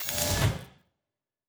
pgs/Assets/Audio/Sci-Fi Sounds/Doors and Portals/Door 3 Close 1.wav at master
Door 3 Close 1.wav